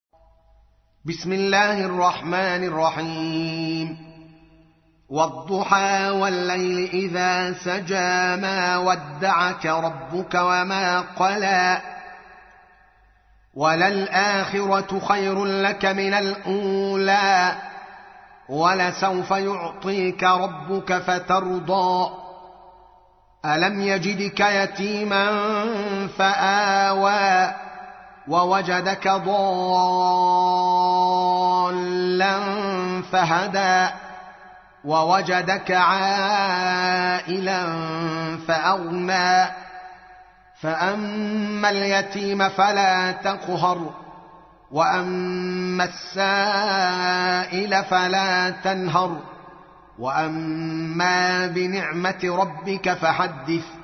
تحميل : 93. سورة الضحى / القارئ الدوكالي محمد العالم / القرآن الكريم / موقع يا حسين